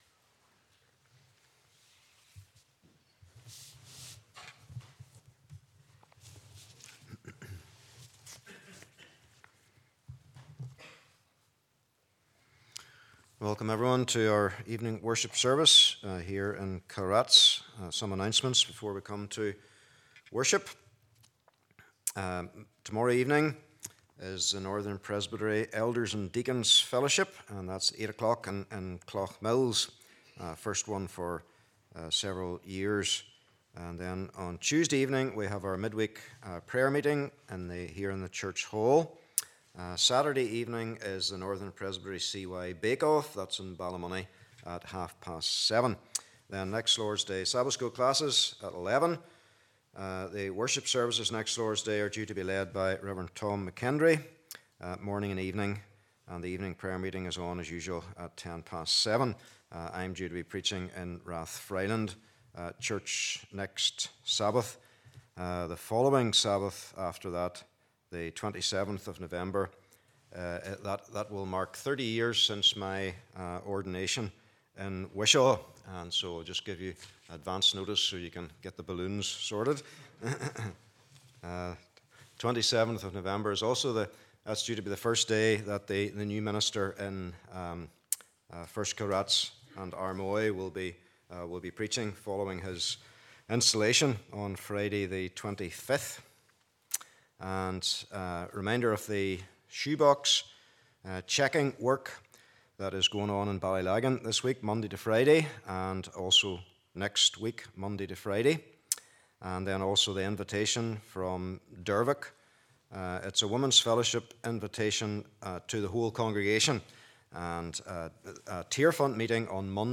13 & 14 Service Type: Evening Service « Jesus the Accessible Saviour Nicodemus